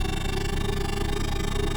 Speed_loop_4.ogg